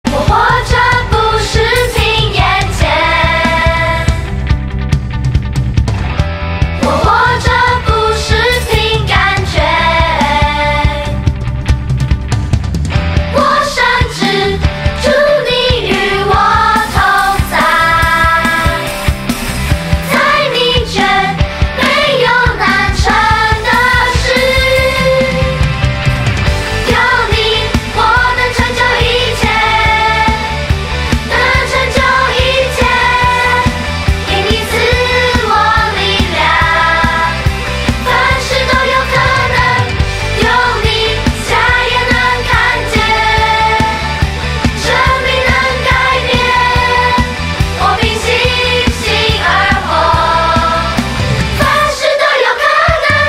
全部商品 / 音樂專區 / 中文專輯 / 兒童敬拜
12首充滿活力與感動的敬拜讚美+ 7首傳遞堅定愛神的精彩MV
聽見孩子真摯的歌聲 可以改變您的心情  看見神所創造的美好